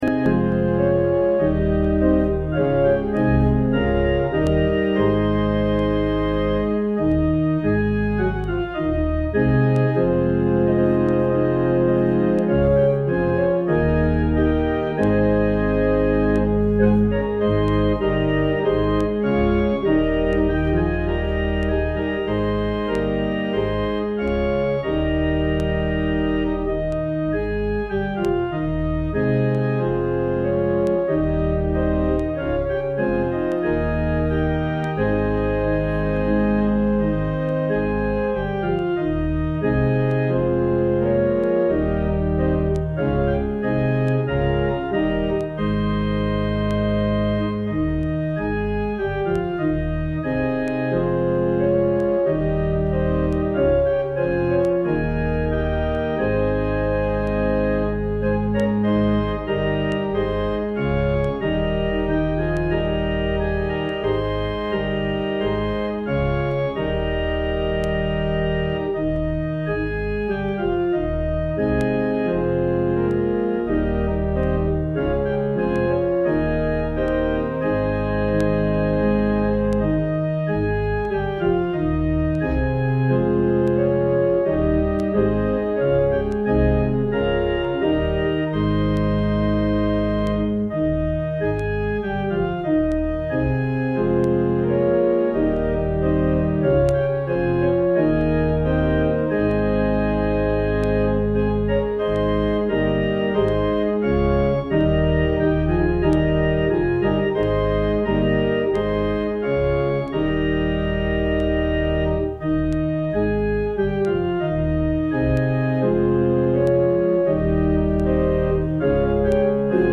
A Message on Submission on Palm Sunday - Pascoag Community Baptist Church